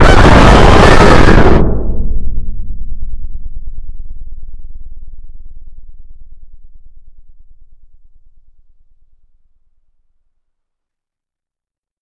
FNAW: High Rollers Jumpscare - Bouton d'effet sonore